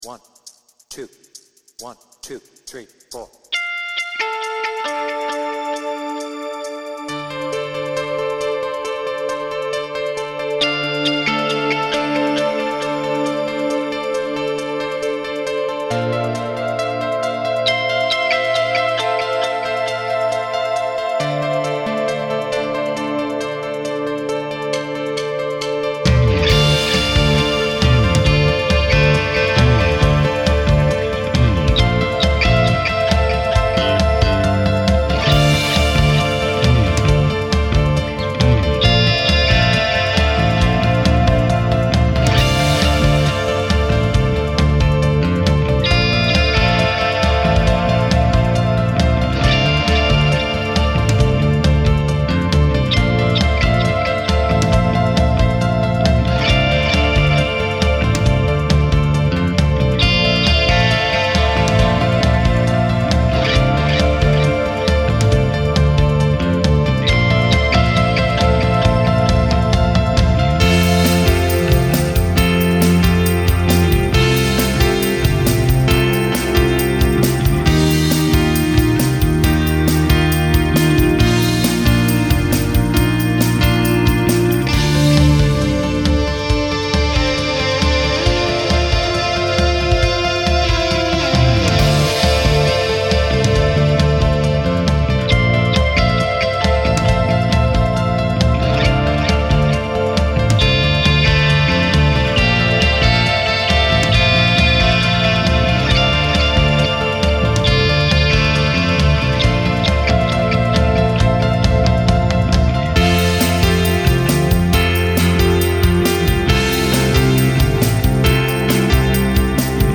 BPM : 136